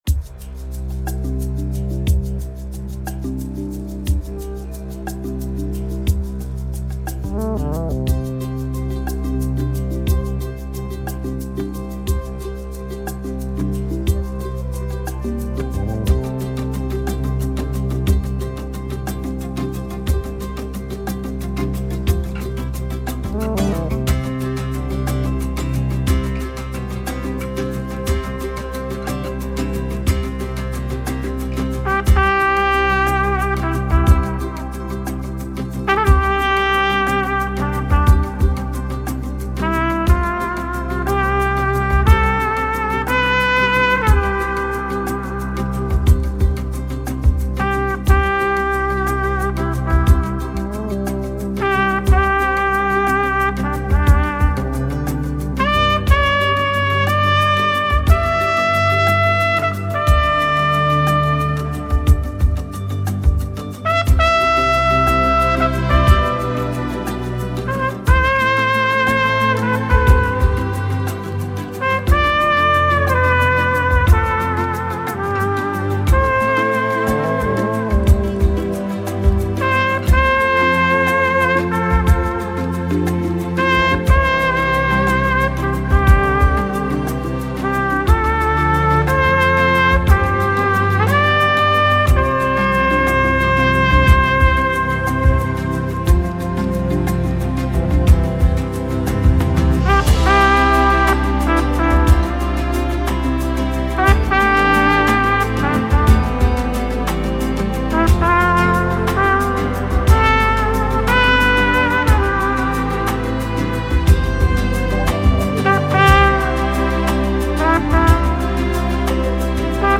Genre : Contemporary Jazz